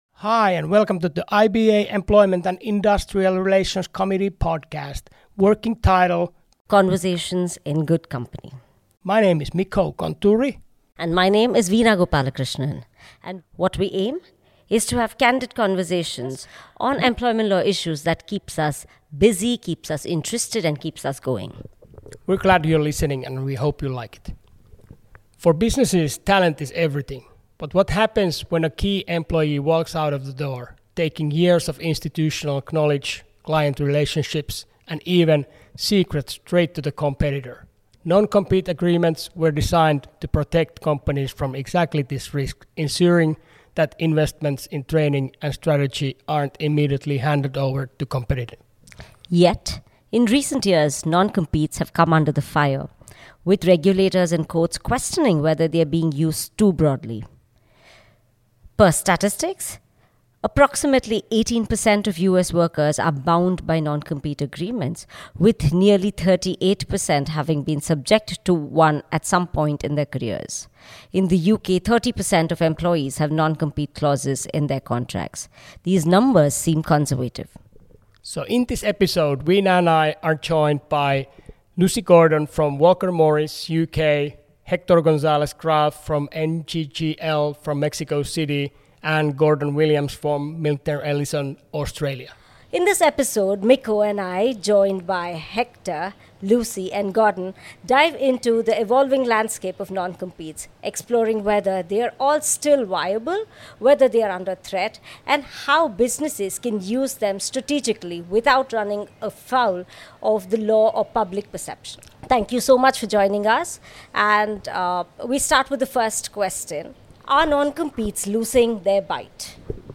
Please join us for this relaxed and insightful conversation…we hope you like it!